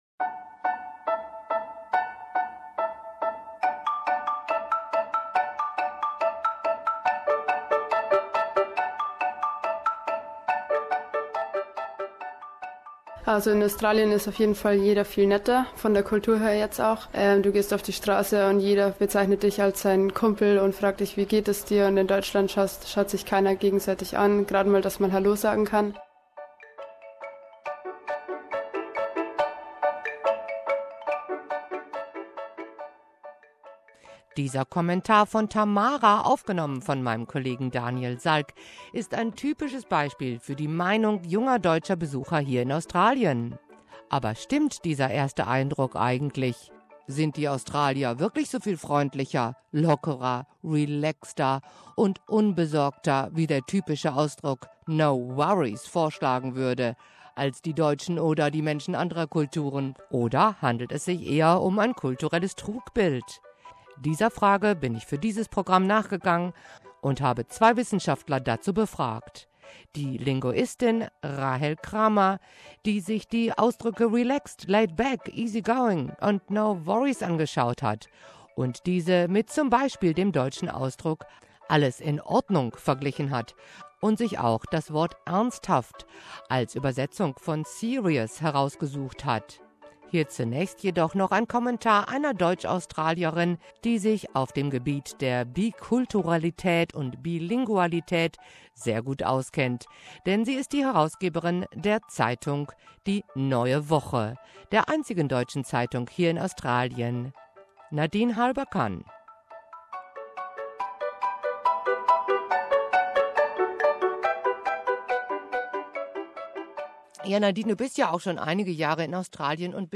und hat dazu zwei Wissenschaftler befragt, eine Linguistin und einen Arbeitspsychologen.